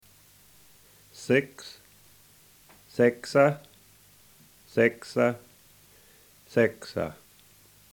Puhoi Egerländer Dialect